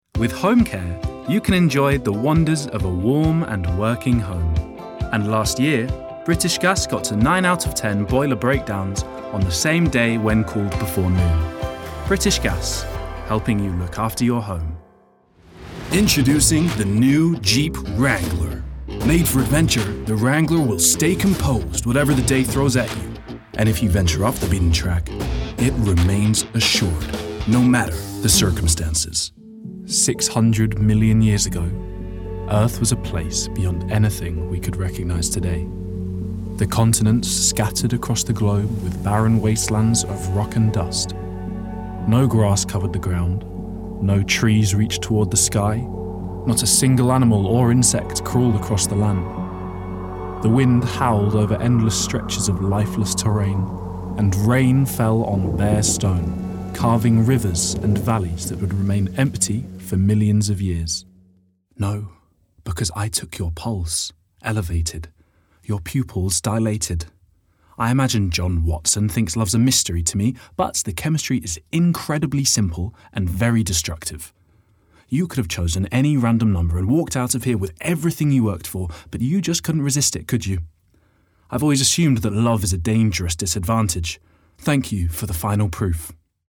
Native voice:
Contemporary RP
Voicereel: